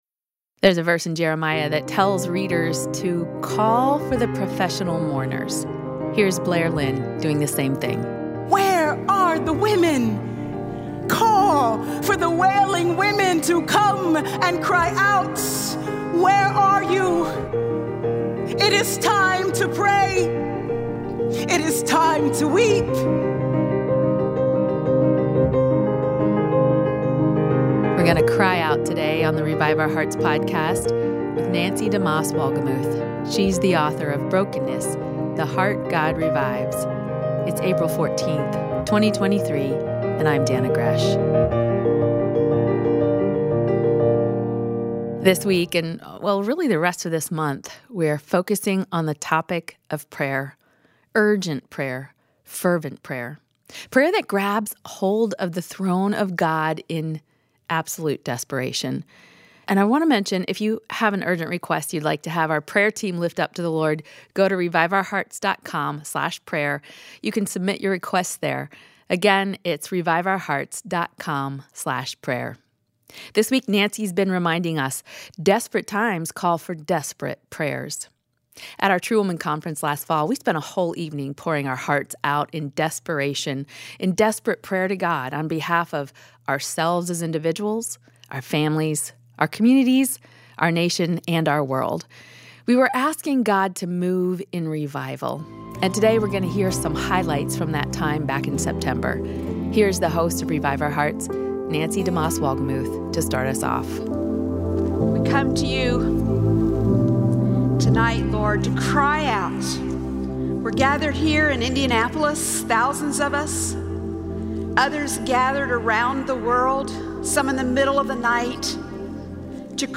A group of desperate women took some time to cry out to the Lord on behalf of their families, churches, and nations last fall. We’ll hear some of their prayers.